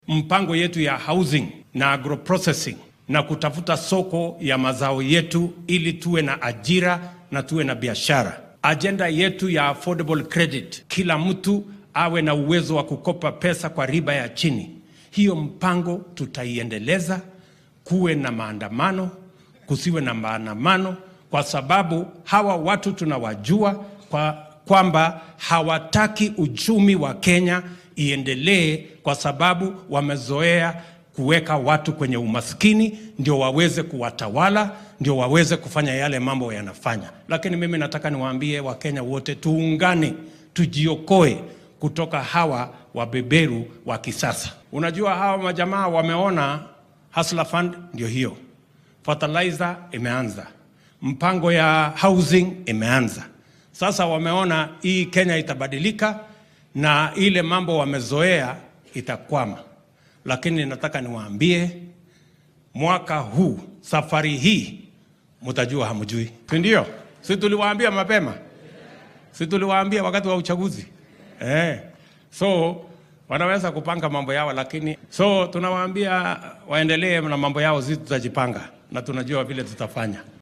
William Ruto ayaa xilli uu ku sugnaa ismaamulka Embu carrabka ku adkeeyay in marnaba aan loo joojin doonin hanjabaadaha mucaaradka oo horay loo sii tallaabsan doono.